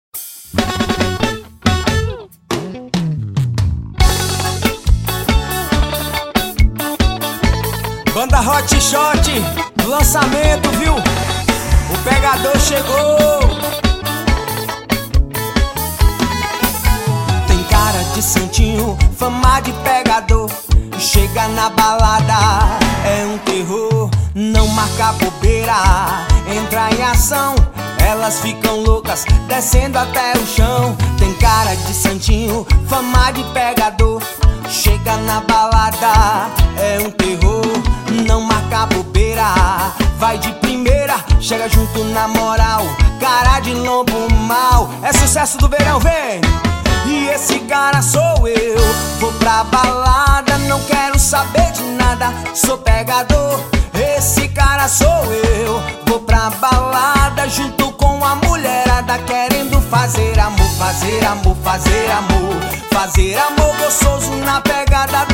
forró
na pegada do forró universitário